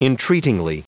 Prononciation du mot : entreatingly
entreatingly.wav